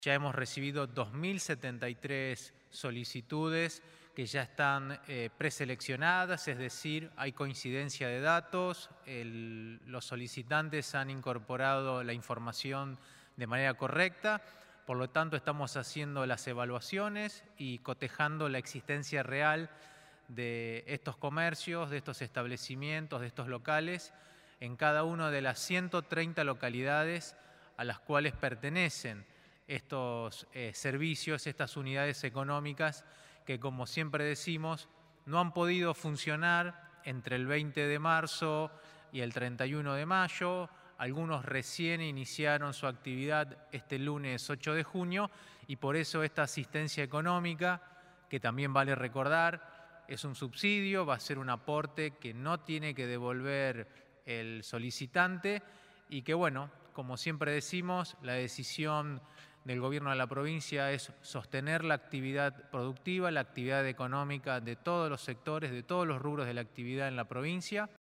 El secretario de Comercio Interior, Juan Marcos Aviano, durante la conferencia.